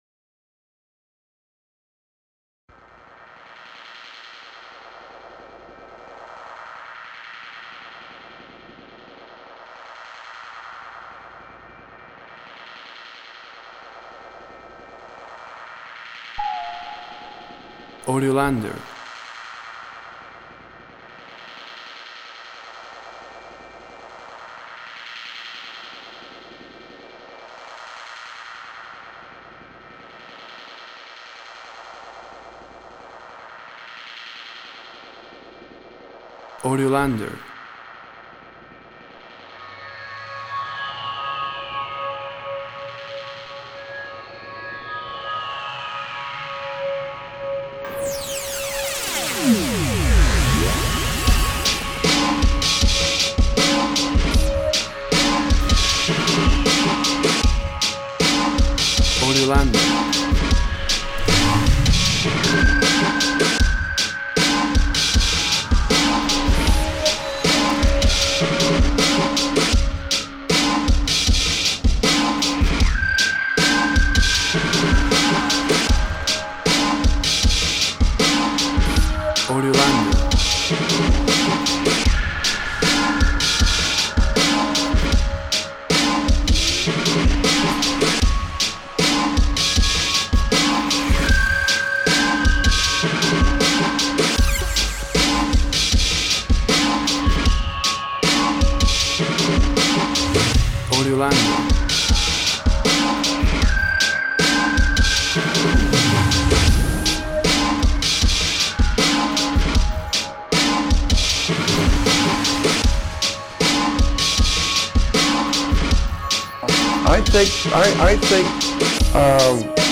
Ideal for environments of energy, agitation and tension.
Tempo (BPM) 85